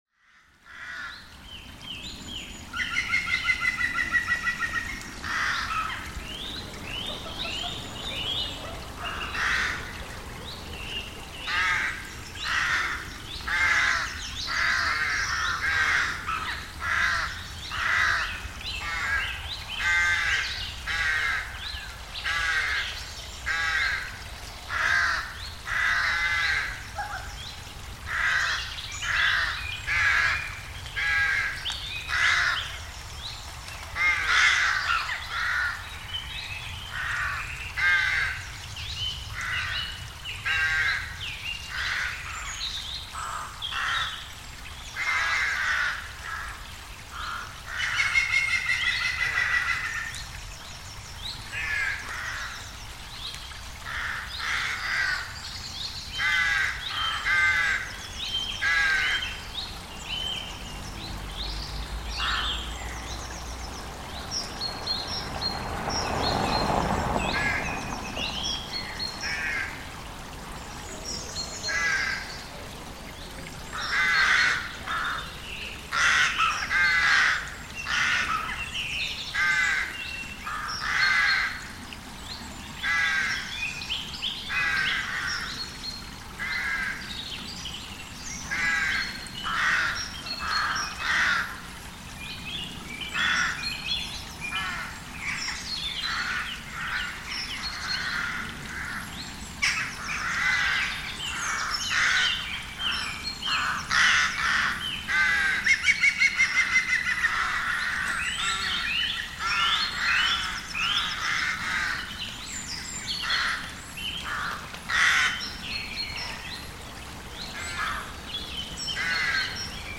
This composition is all about creating a brand new sense of place by combining elements of two recordings - crows nesting by a stream in some woods in Oxford, UK, and cars passing over a bridge by a cafe in Vicenza, Italy. There's not a road going through the Oxford woods, and yet we hear cars passing by in the soundscape. There are no trees, crows or woodpeckers in this part of Vicenza, and yet we hear them. Through sound, we have created a new, impossible location that exists simultaneously both in Oxford and Vicenza, but which also exists in neither.